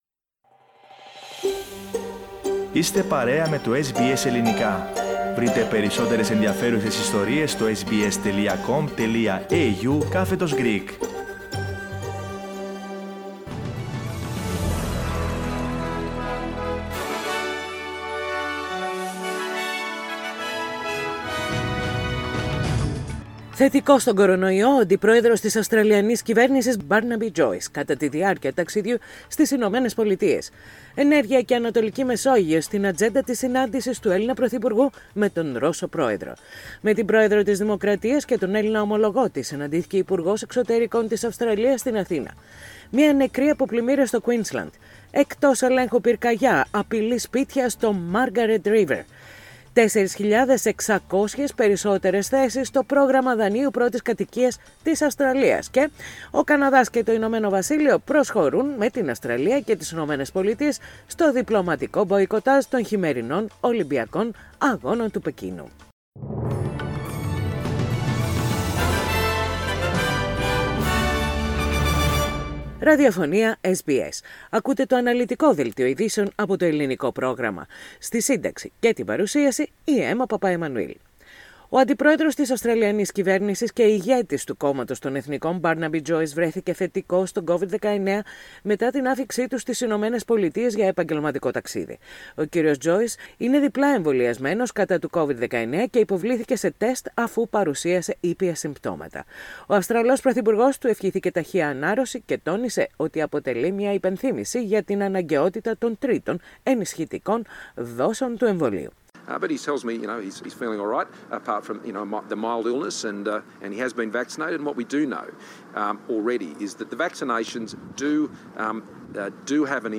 News in Greek. Source: SBS Radio